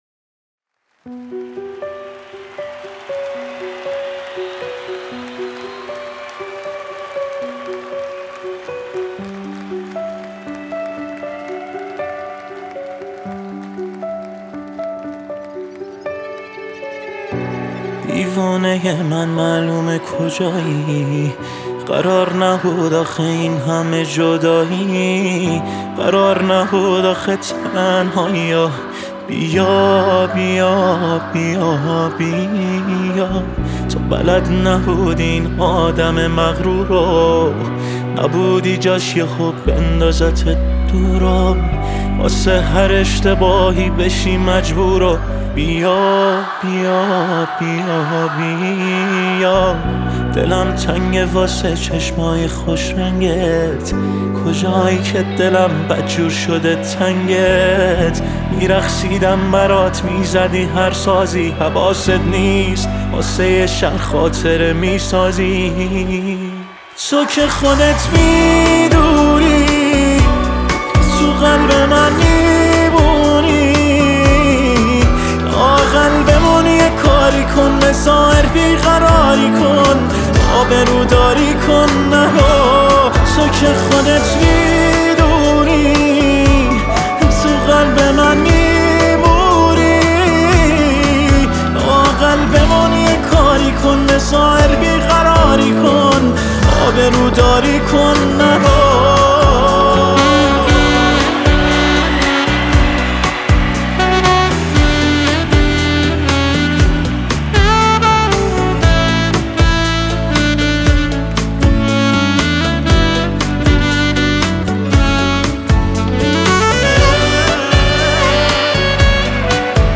عاشقانه و غمگین
(Acoustic Version)